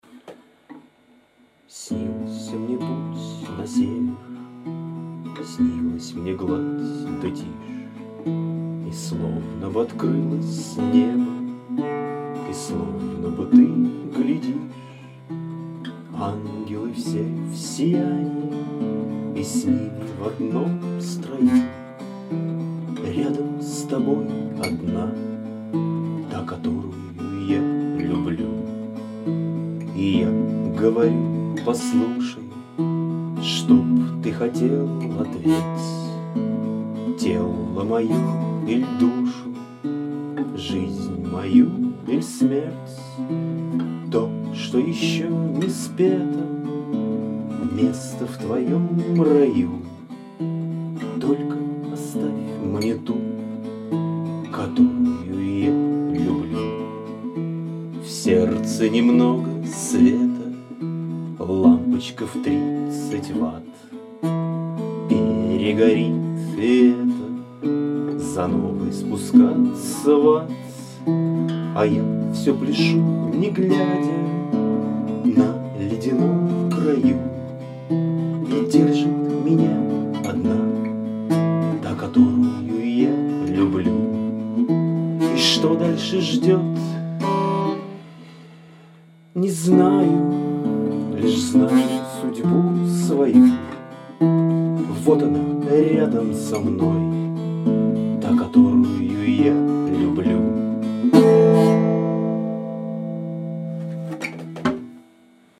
Низковато взял.
Согласен, чегот низенько пошел
Тембр приятный.